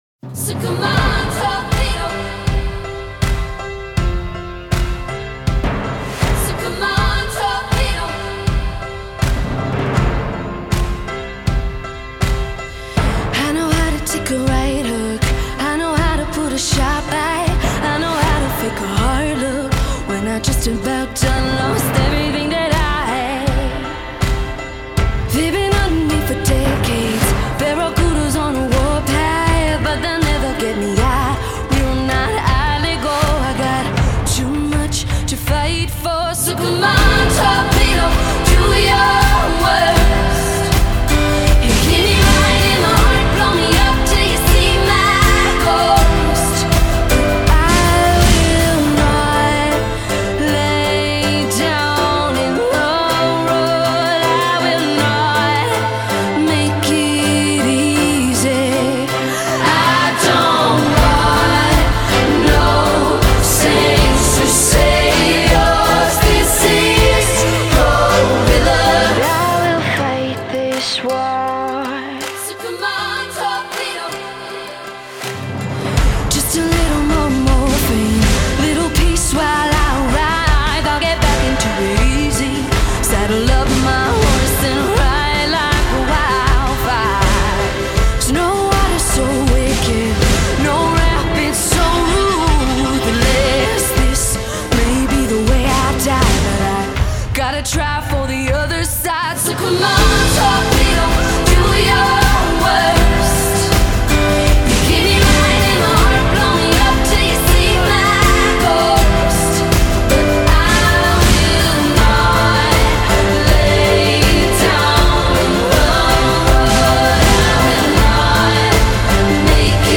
anthem tune
beautifully layered vocals
poignant piano  laced with hard hitting drums
Most upbeat track on the EP